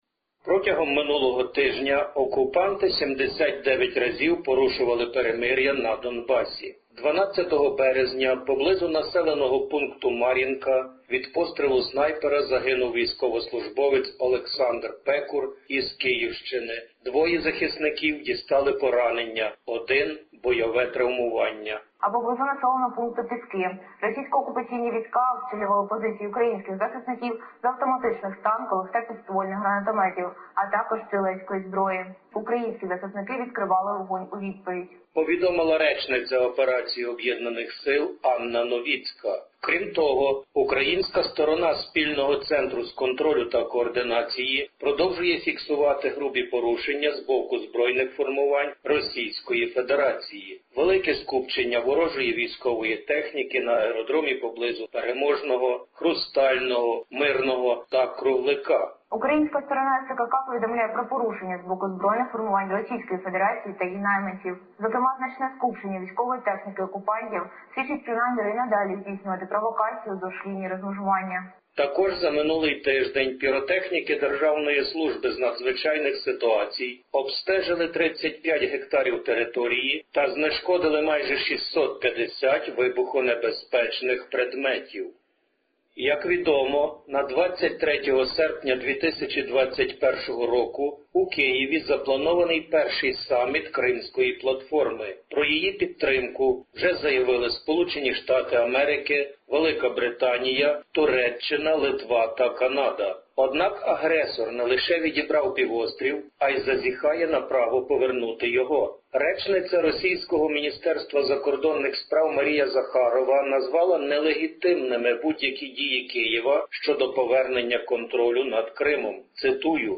18/03/2021 - добірка актуальних новин для радіослухачів SBS Ukrainian - вістки добрi й не дуже із життя-буття воюючої із Росією України, поміж якими новітня війна триває уже 7 років.